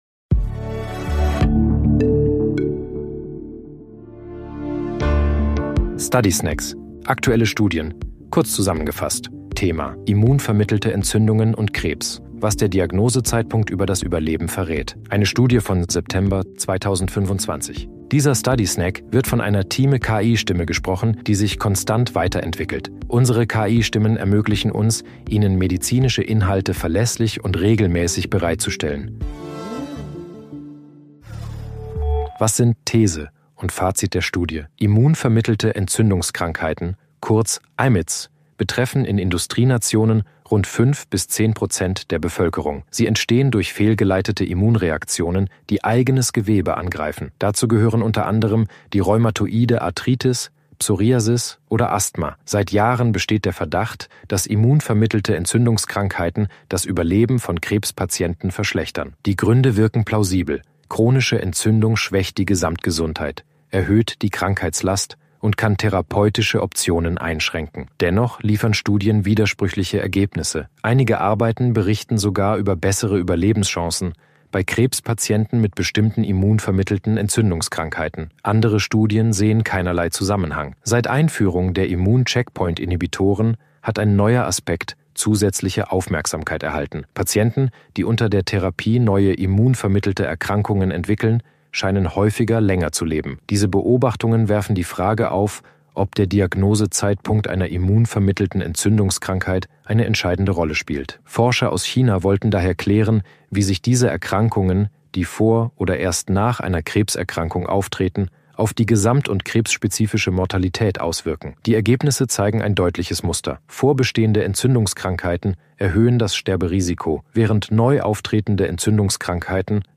In diesem Audio sind mit Hilfe von künstlicher
Intelligenz (KI) oder maschineller Übersetzungstechnologie